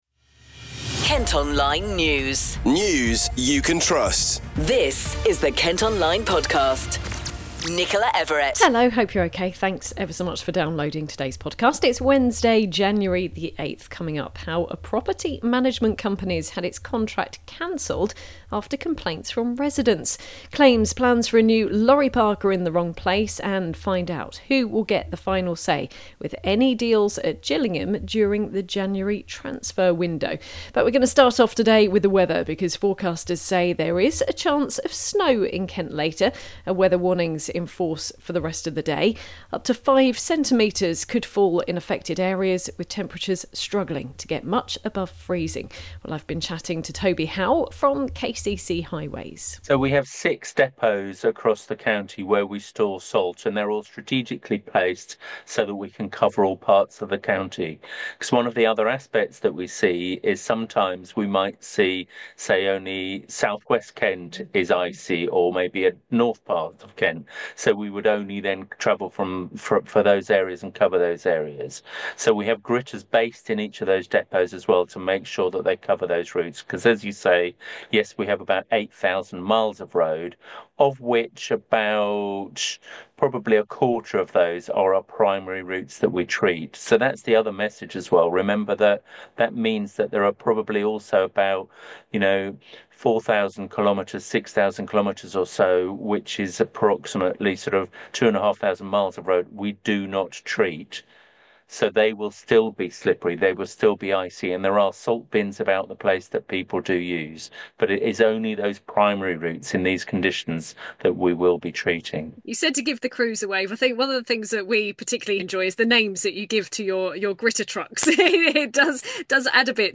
A yellow weather warning’s in force and we’ve been speaking to KCC Highways and a forecaster from the Met Office.
Hear what people have been saying to the local democracy reporting service.